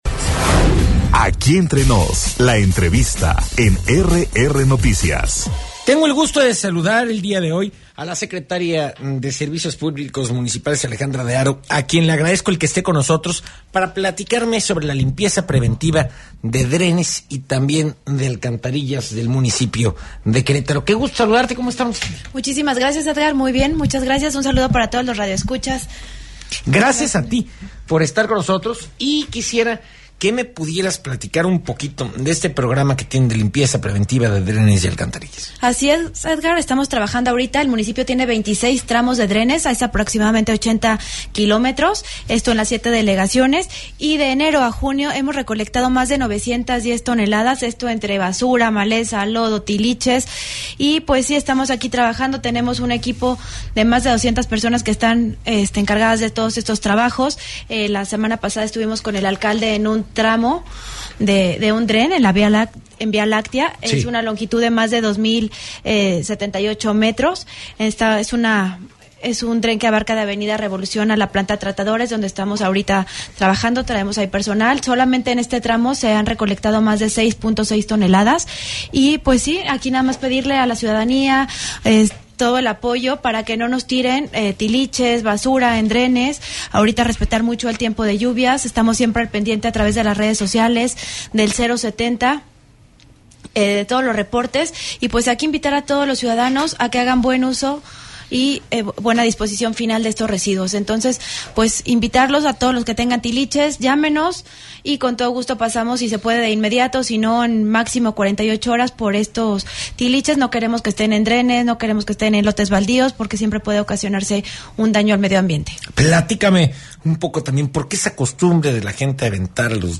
EntrevistasMultimediaMunicipiosPodcast
«Avanza limpieza de drenes en Querétaro», en entrevista la Secretaria de Servicios Públicos Municipales, Alejandra Haro
ENTREVISTA-ALEJANDRA-HARO-DE-LA-TORRE.mp3